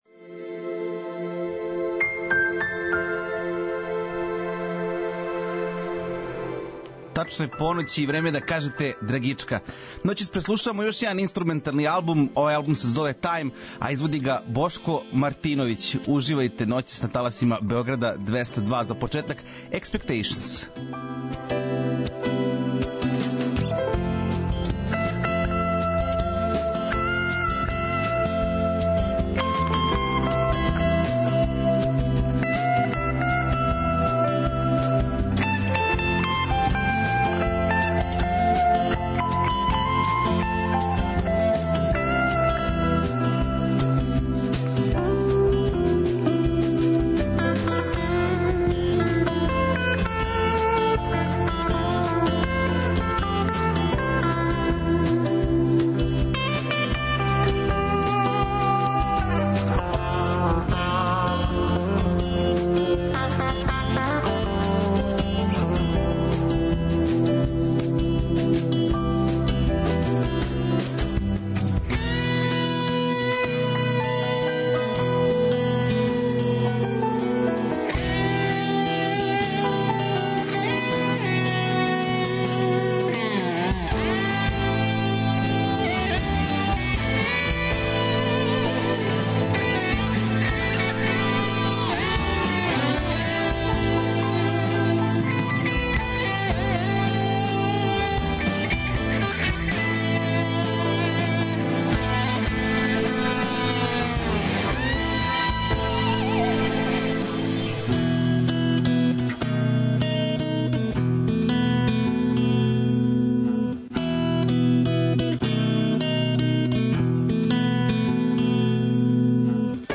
гитаристу и композитора
фјужн џез албум са пет инструментала